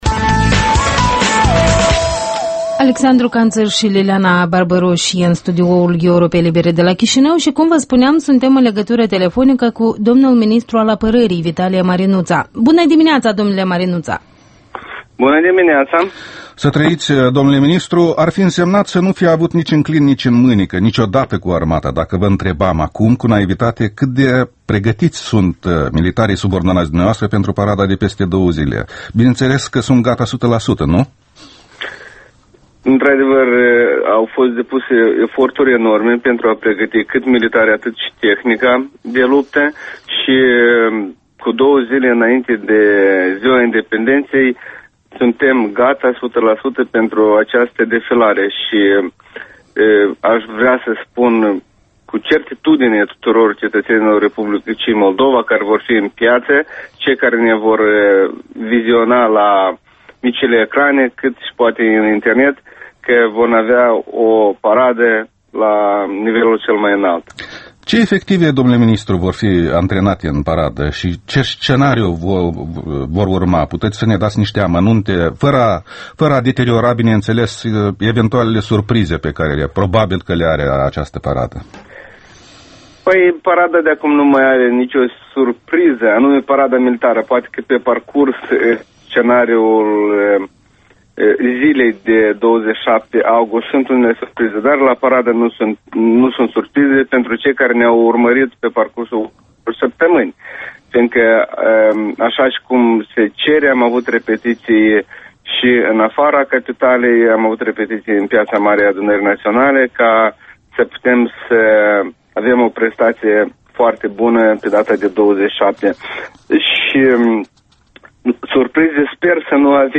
Interviul matinal la Europa Liberă cu Ministrul Apărării, Vitalie Marinuţa